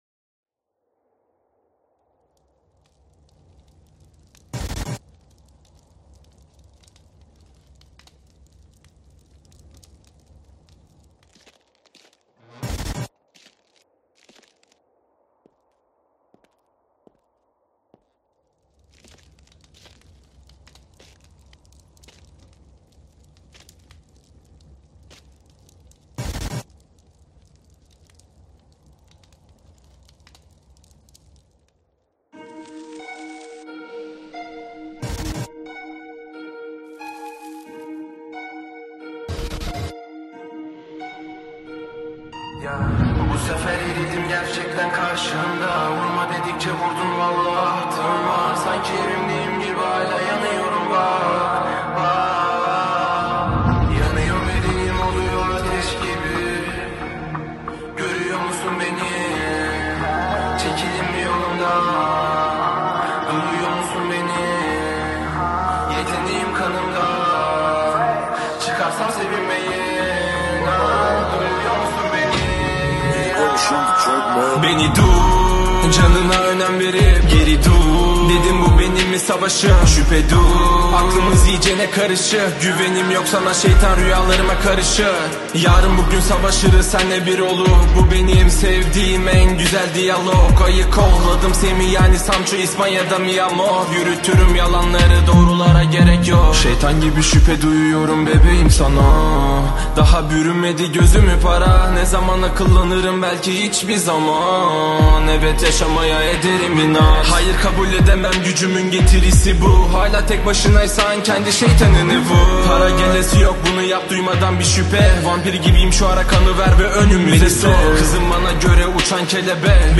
Жанр: Турецкие песни